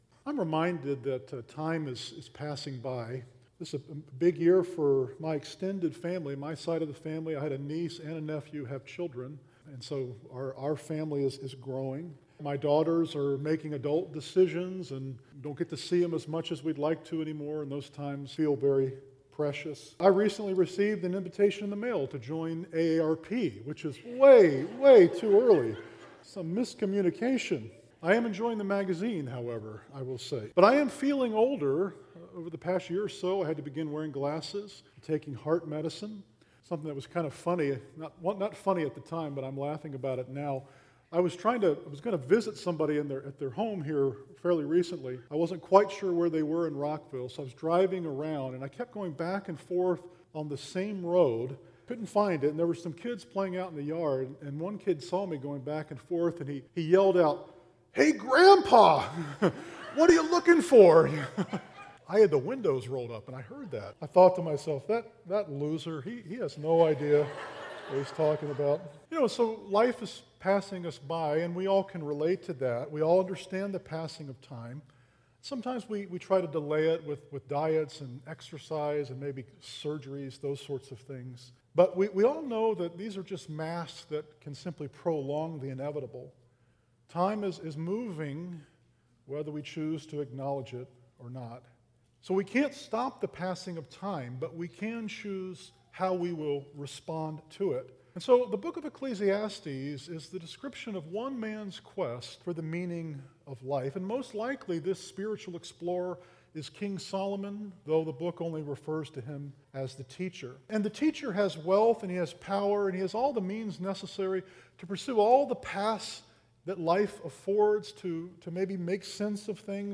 Sermon: A Time for Change